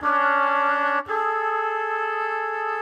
GS_MuteHorn_85-DA.wav